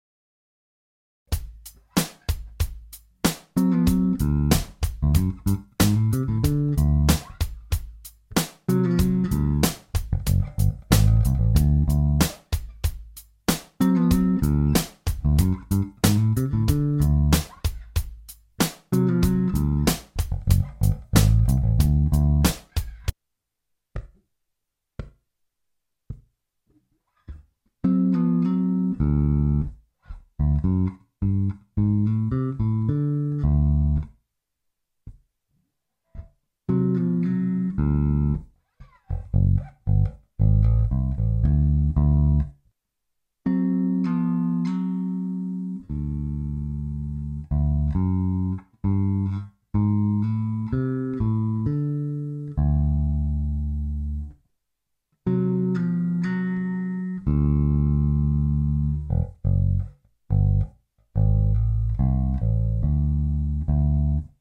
L87 Bass simple chords or doublestops in D7
L87-Doublestops-in-D7.mp3